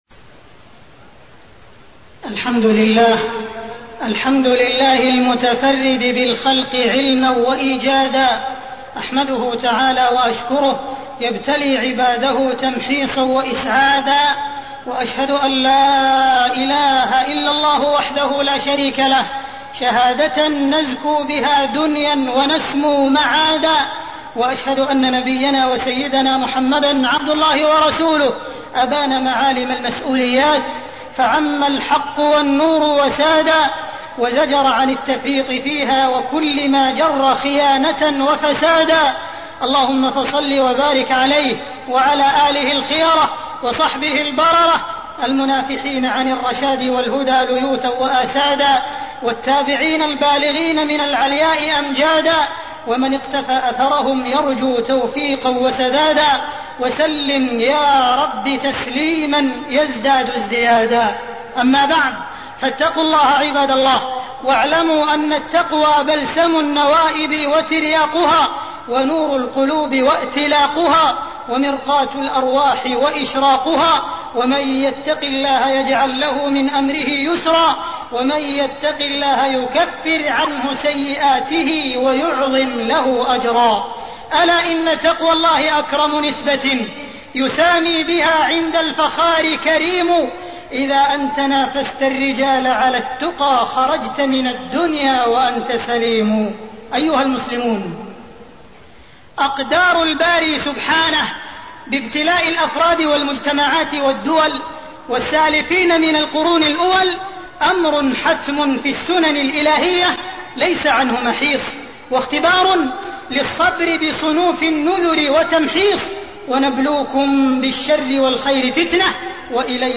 تاريخ النشر ٢٢ محرم ١٤٣٠ هـ المكان: المسجد الحرام الشيخ: معالي الشيخ أ.د. عبدالرحمن بن عبدالعزيز السديس معالي الشيخ أ.د. عبدالرحمن بن عبدالعزيز السديس بين المحن والمنن